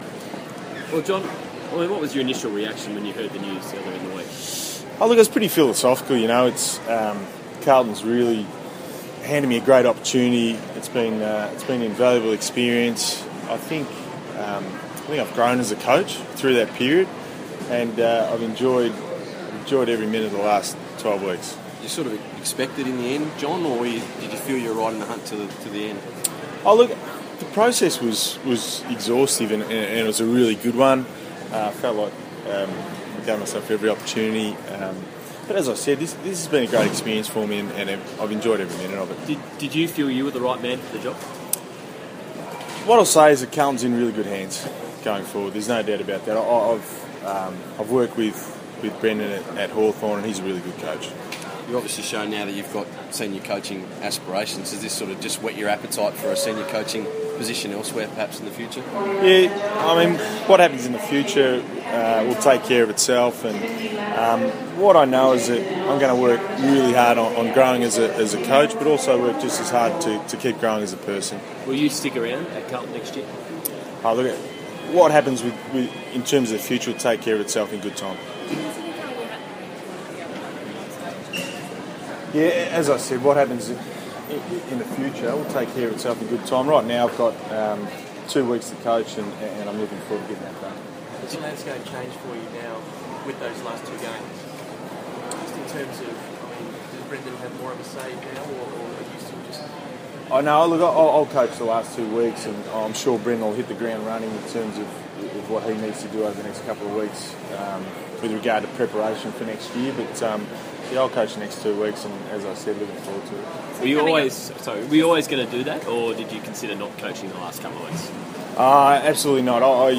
speaks to the media at Melbourne Airport